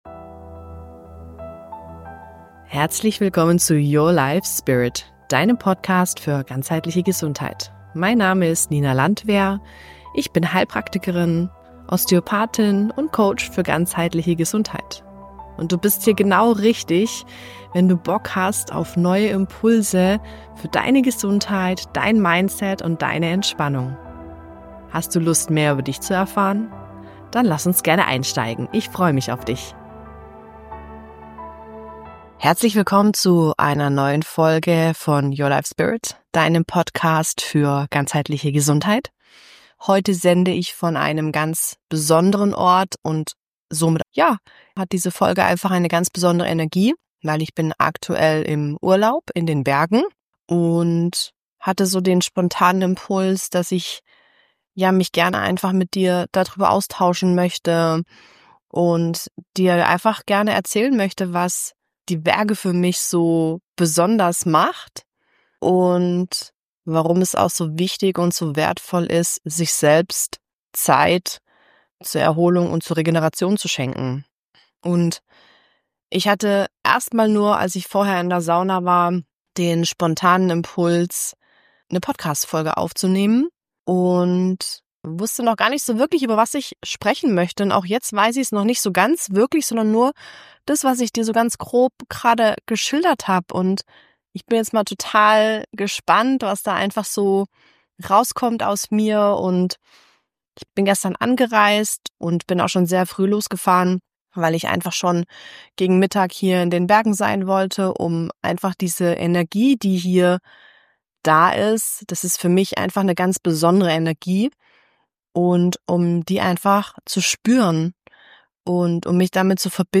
In dieser Folge nehme ich Dich mit in meine Gedankenwelt – direkt aus den Bergen.